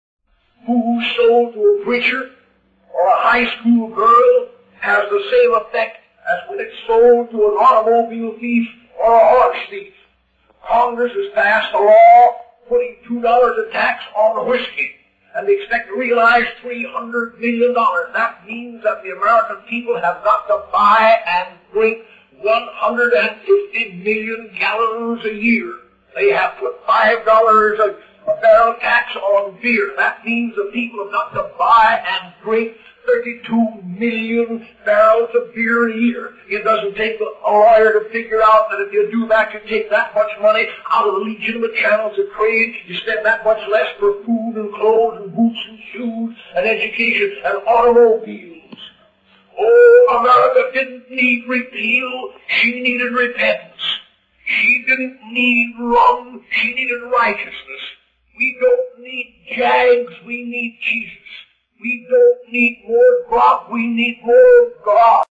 In this sermon, the preacher highlights the negative impact of excessive taxation on alcohol in America.